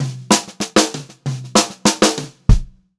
96CL3FILL2-R.wav